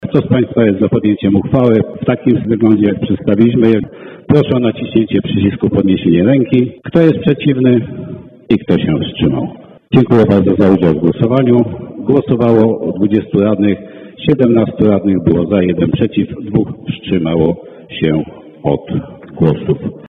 Posłuchaj (to głos przewodniczącego rady Jarosława Gowina)